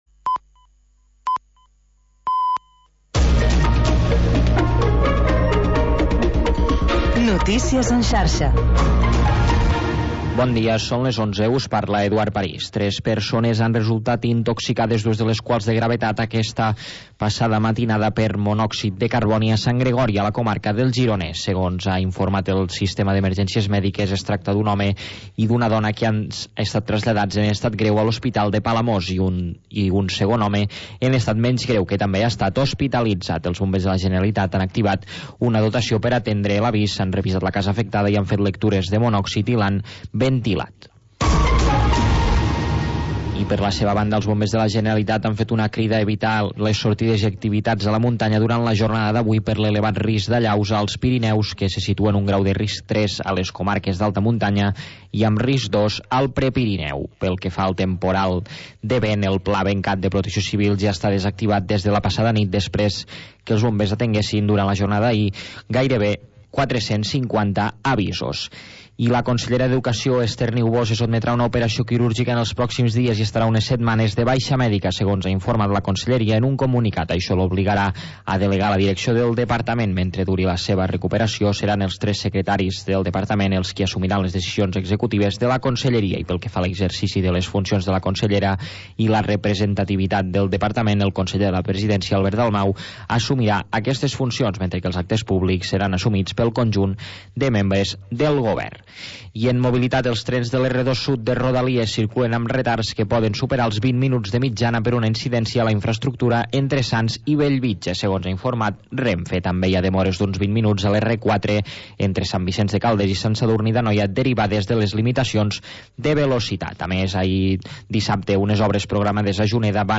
Havanera, cant de taverna i cançó marinera. obrint una finestra al mar per deixar entrar els sons més mariners